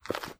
High Quality Footsteps
STEPS Dirt, Walk 24.wav